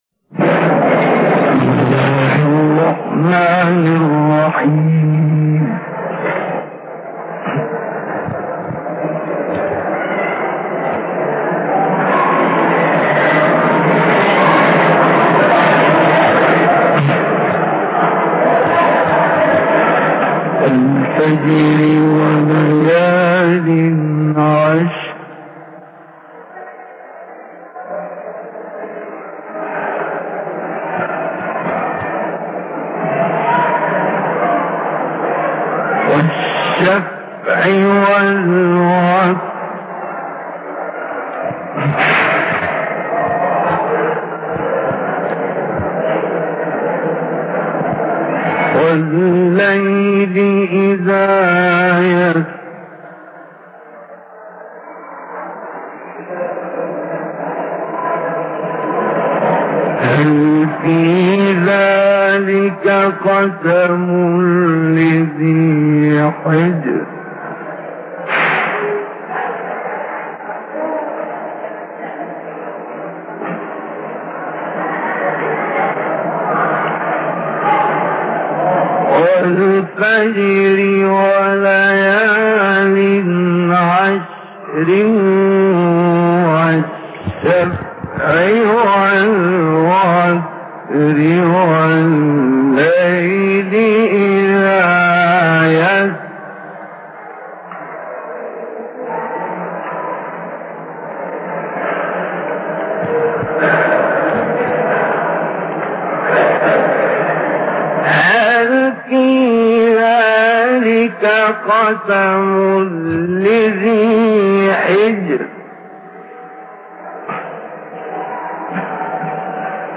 Short Surahs from Ala- Hussein Mosque in Cairo in 1964